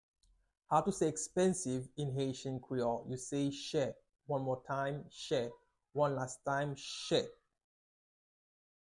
Listen to and watch “Chè” audio pronunciation in Haitian Creole by a native Haitian  in the video below:
7.How-to-say-Expensive-in-Haitian-Creole-–-Che-pronunciation.mp3